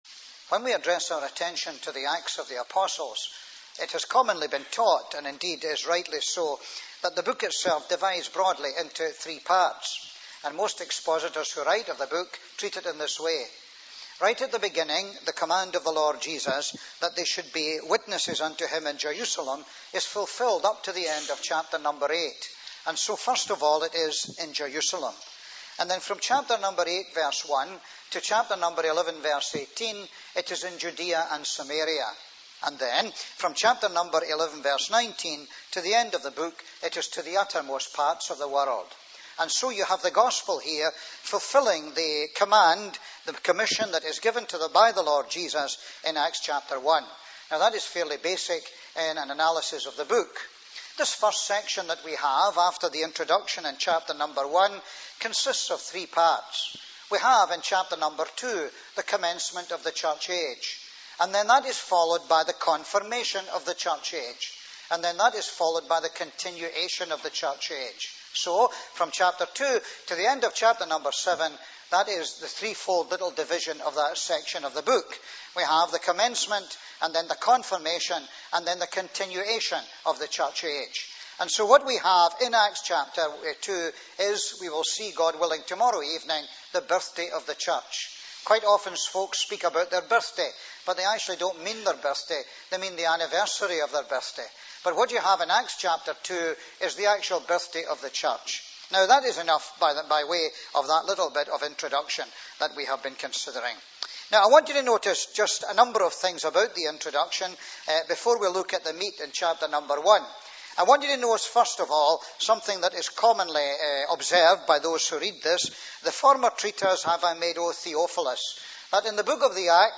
(Message preached Monday 11th June 2006)